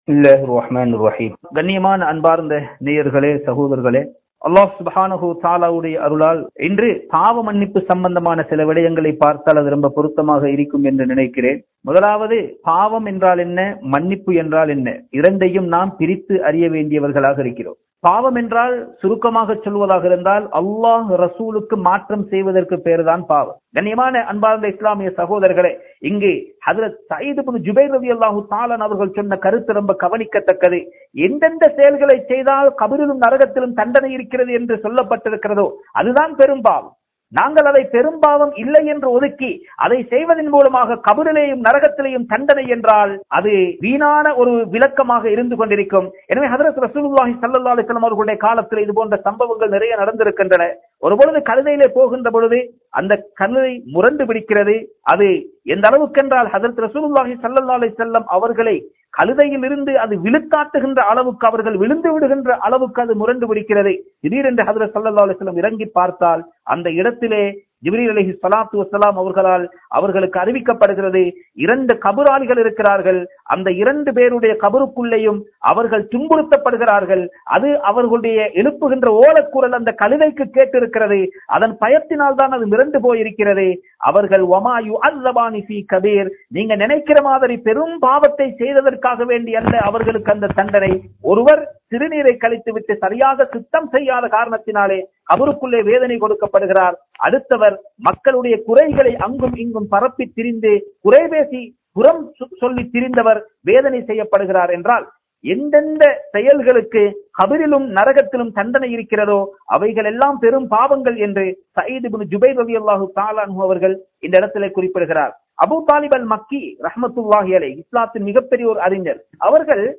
Bayans